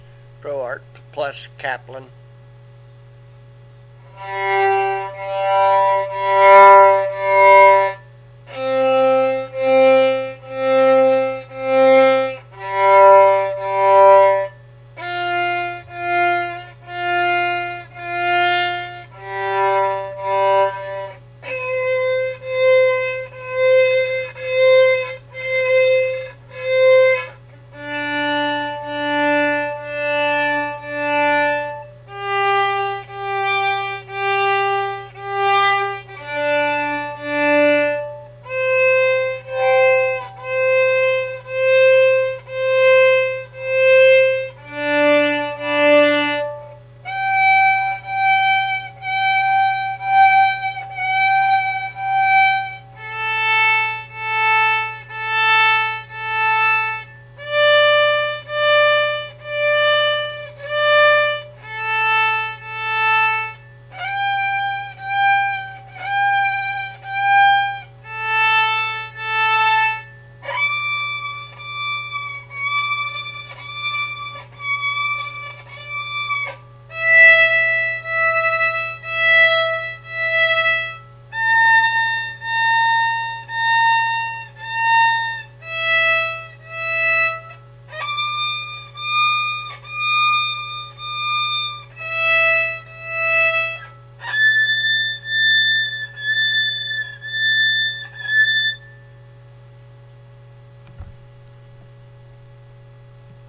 Here are audio files for 6 sets of strings so you can hear what they sound like.